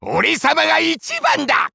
Wario's voice from the official Japanese site for WarioWare: Move It!
WWMI_JP_Site_Wario_Voice.wav